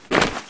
jackboot2.ogg